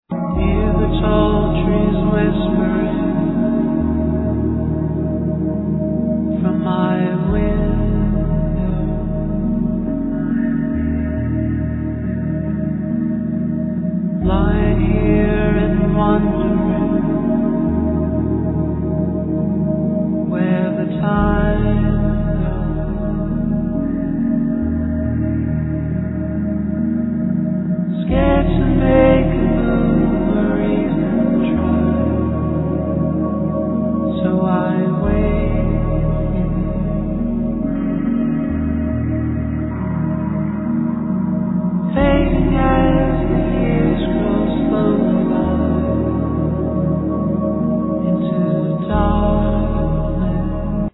Synthesizer, Voice